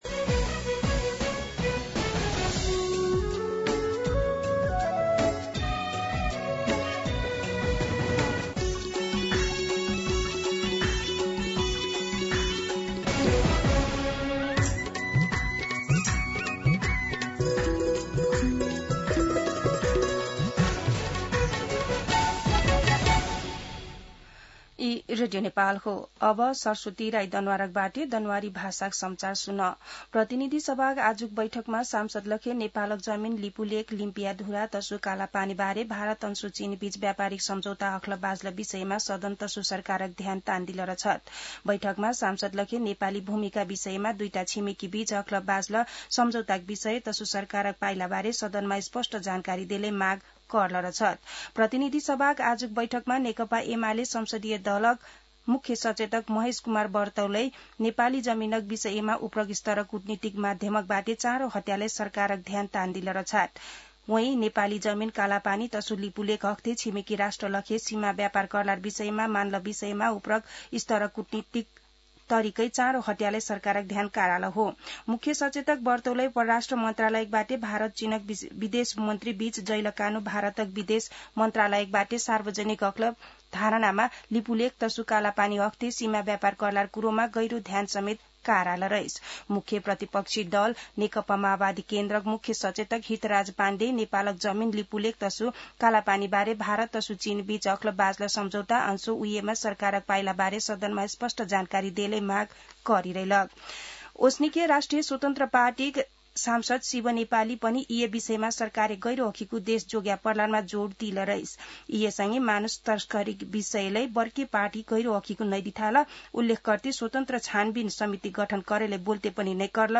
दनुवार भाषामा समाचार : ५ भदौ , २०८२